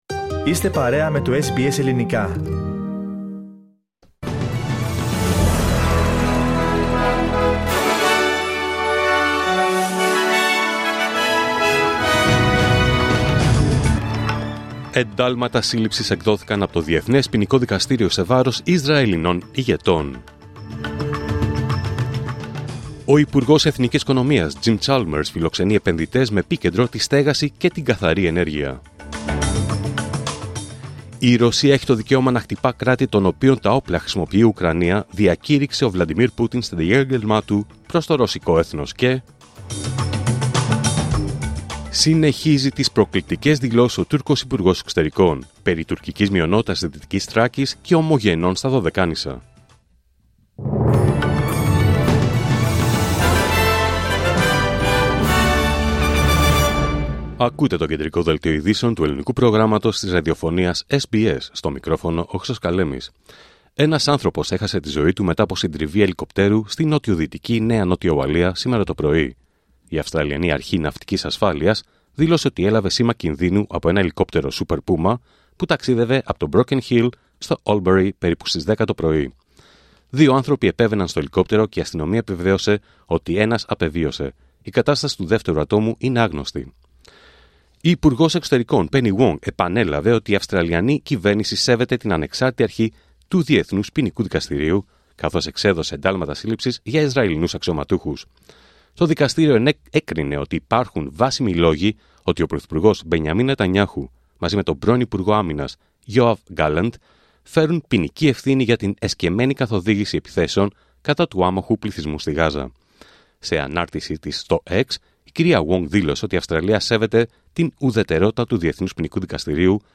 Δελτίο Ειδήσεων Παρασκευή 22 Νοέμβριου 2024